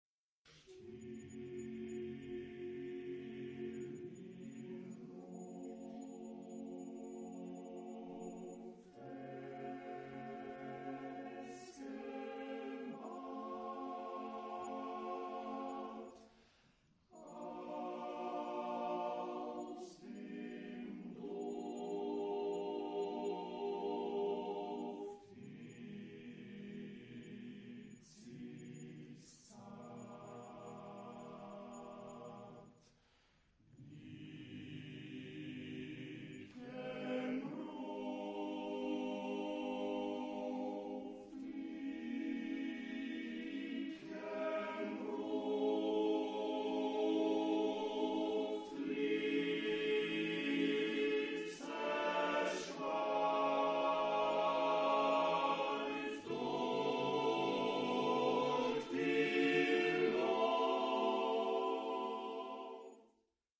Genre-Stil-Form: Chorlied
Chorgattung: TTBB  (4 Männerchor Stimmen )